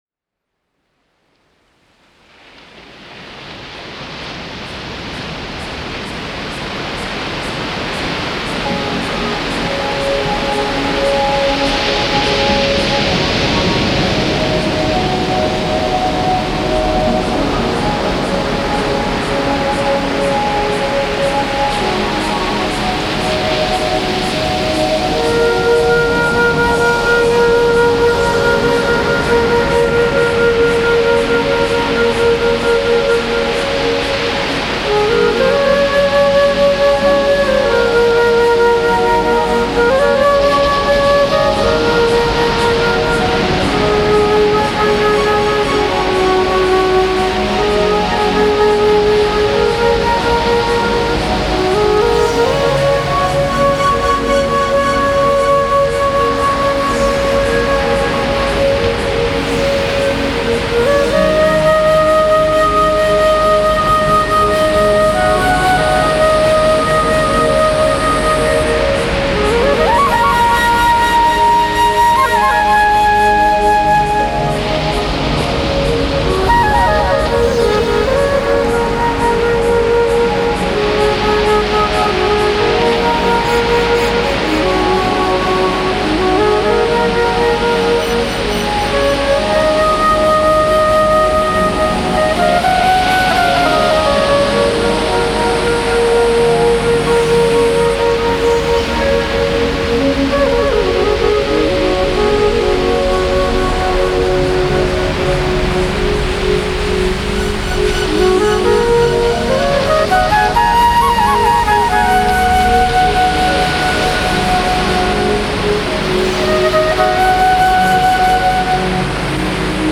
flute improvisations